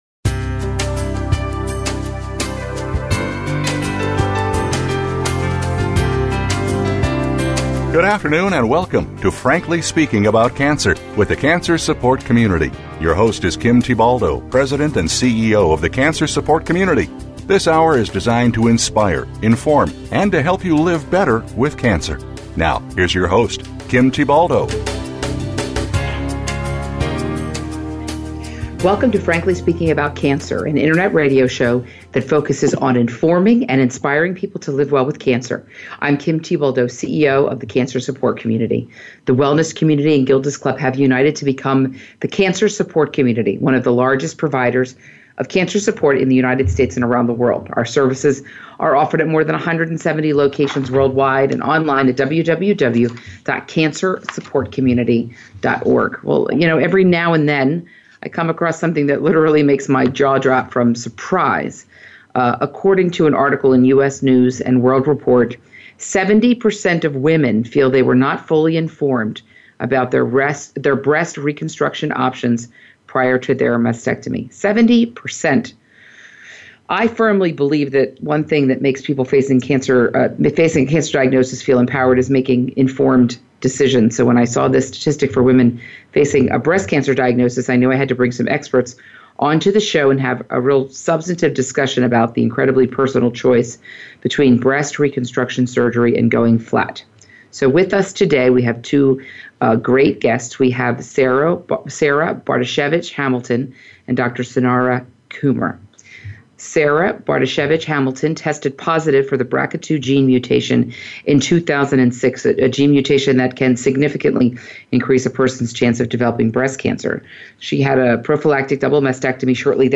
70% of women feel they were not fully informed about their breast reconstruction options prior to their mastectomy. Two experts join us today for a substantive discussion about the incredibly personal choice between breast reconstruction surgery and going flat